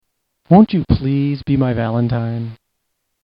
Will you be my Valentine- Adult Male Voice